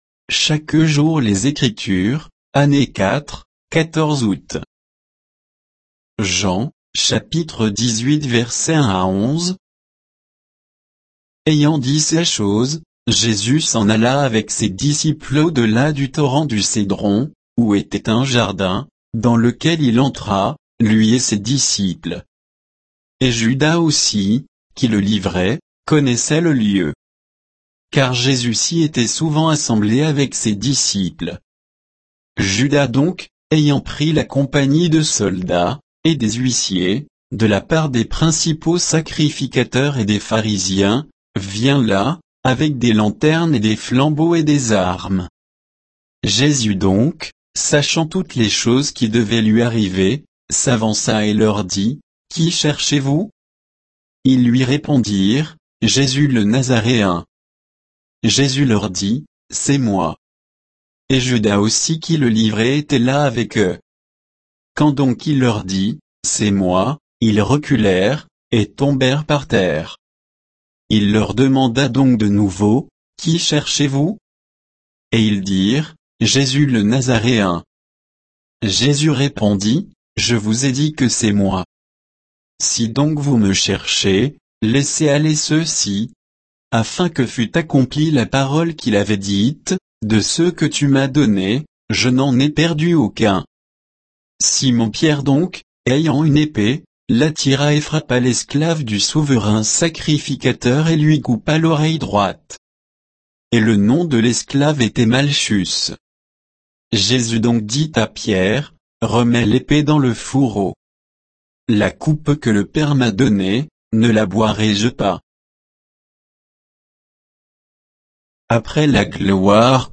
Méditation quoditienne de Chaque jour les Écritures sur Jean 18